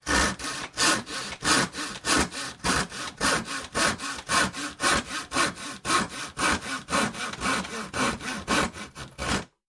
in_copingsaw_sawing_02_hpx
Coping saw cuts various pieces of wood. Tools, Hand Wood, Sawing Saw, Coping